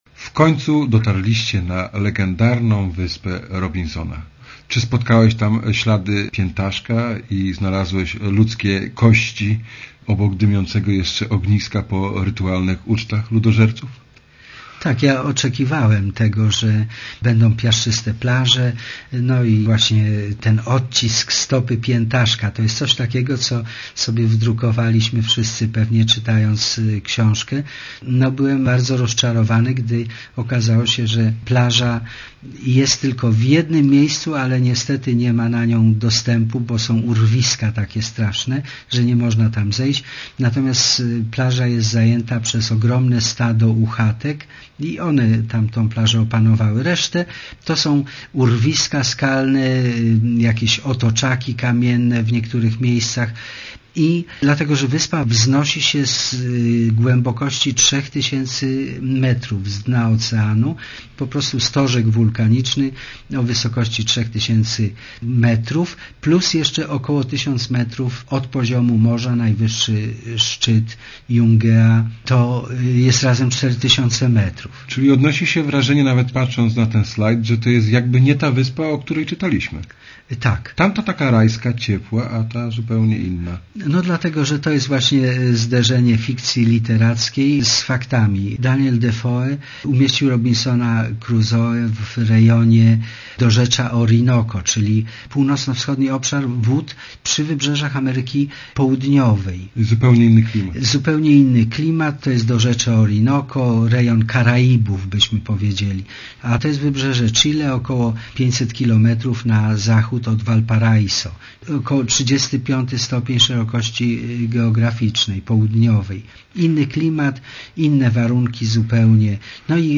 żeglarz a zarazem świetny gawędziarz, opowiada o pacyficz- nym odcinku drugiego rejsu "Marii" dookoła świata.